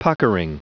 Prononciation du mot puckering en anglais (fichier audio)
Prononciation du mot : puckering